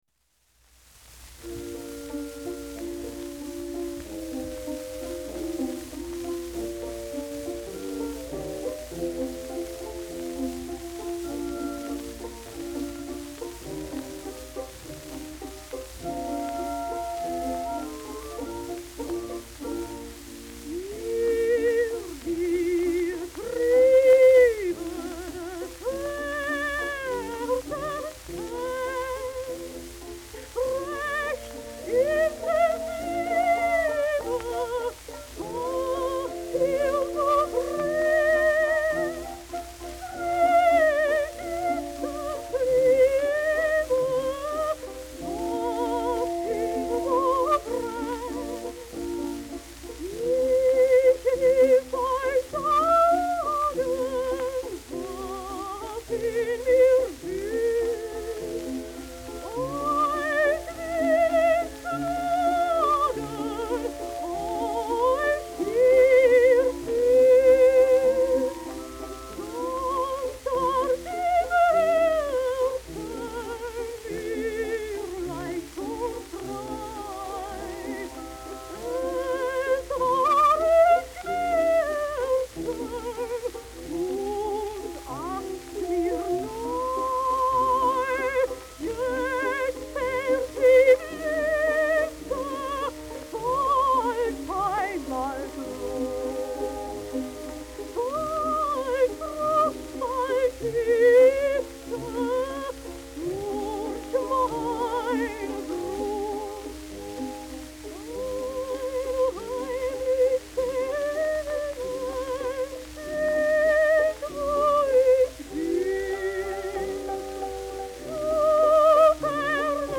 сопрано